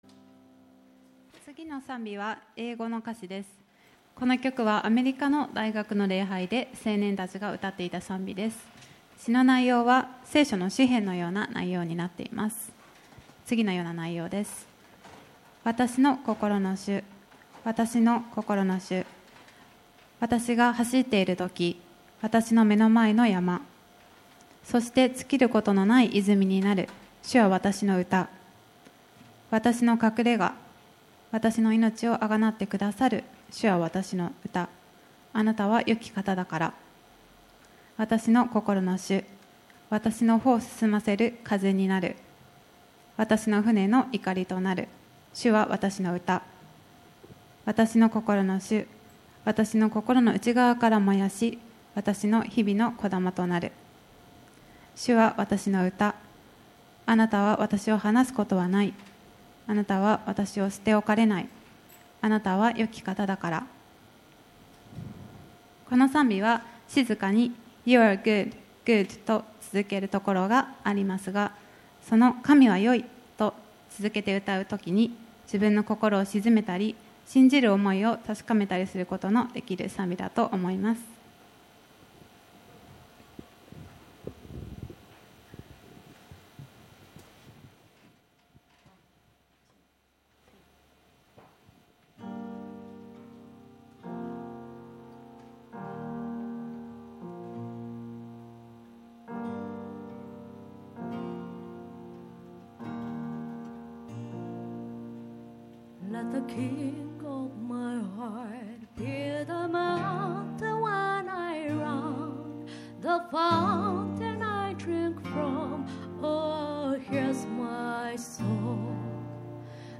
青年月間特別礼拝Ⅰ
青年会奉唱「King Of My Heart」：